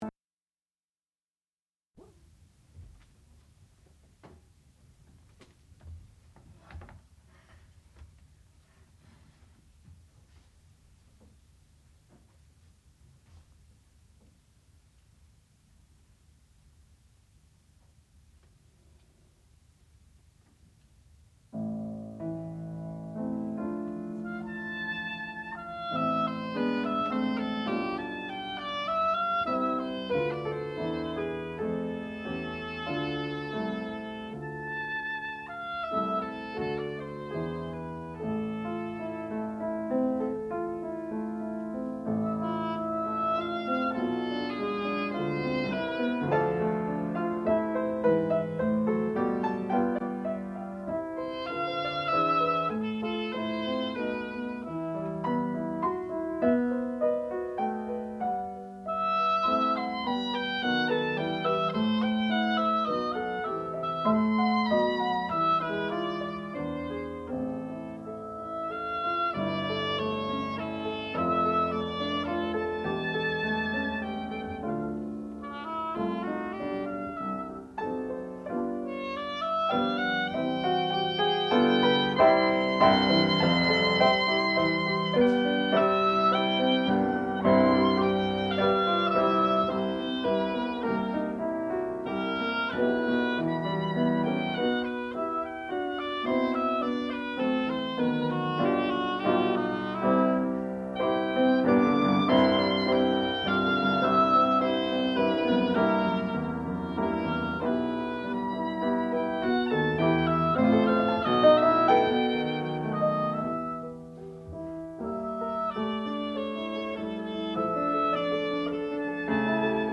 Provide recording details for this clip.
Advanced Recital